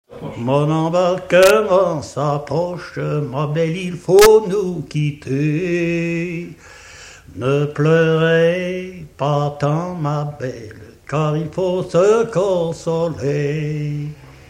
Cortège de noce
Pièce musicale inédite